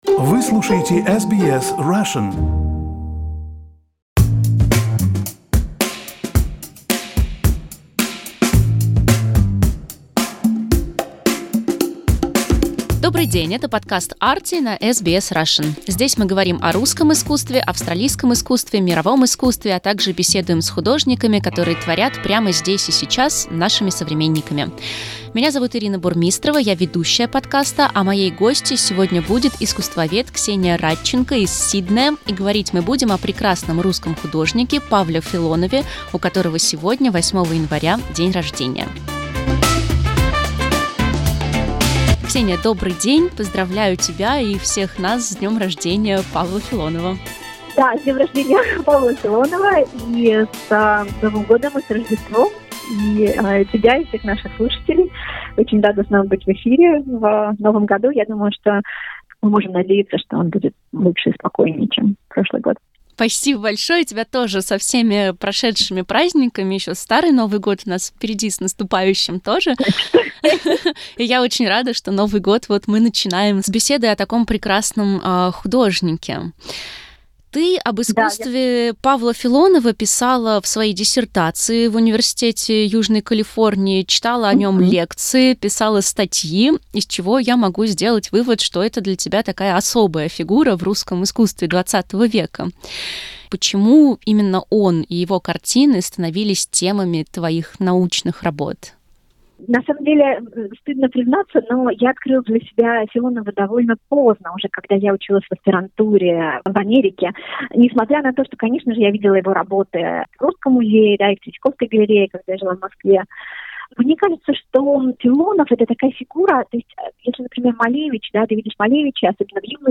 Рассказывает искусствовед